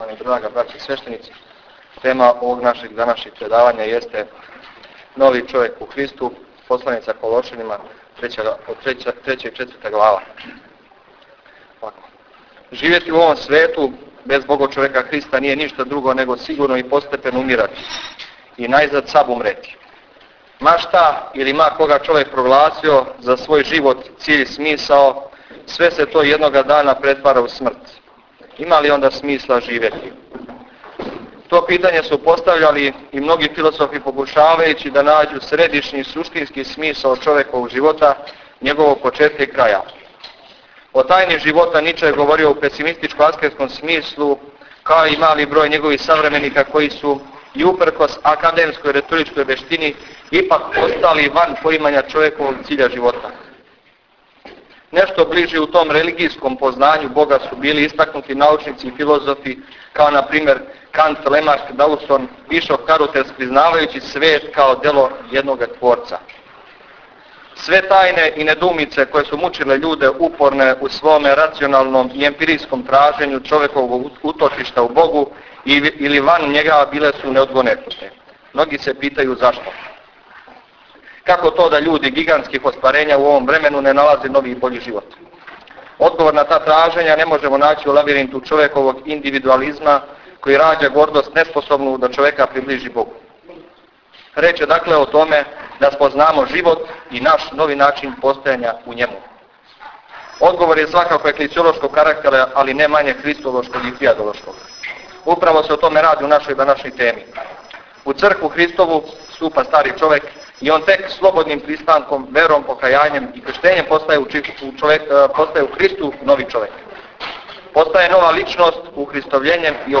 је прочитао свој реферат на тему Нови човек у Христу-Колошанима 3. и 4. глава, после чега је отпочела једна прилично квалитетна дискусија о ономе што смо у том реферату чули, а у којој су активно учествовали сви присутни.